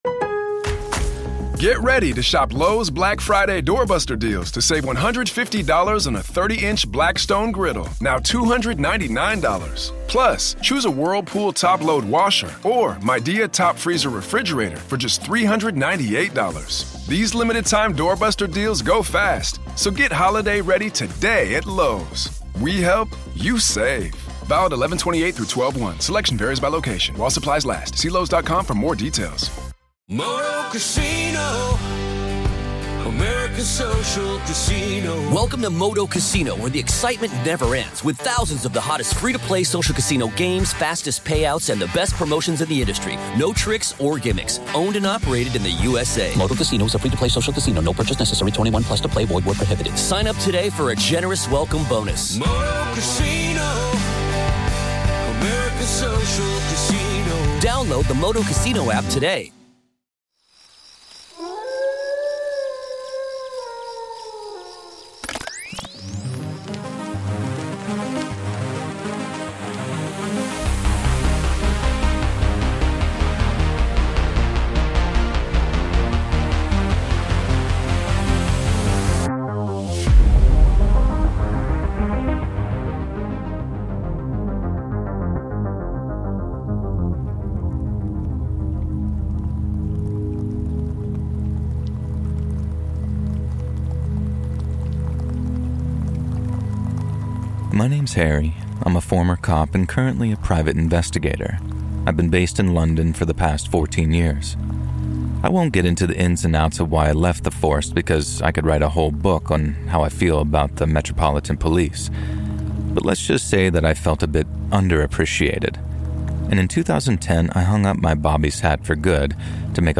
THE CASE THAT CHANGED ME FOREVER | 5 TERRIFYING True Scary Stories / Rain Ambience | EP 307
This episode includes narrations of true creepy encounters submitted by normal folks just like yourself. Today you'll experience horrifying stories about private investigators & craigslist encounters